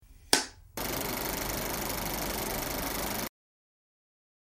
Download Filmstrip sound effect for free.
Filmstrip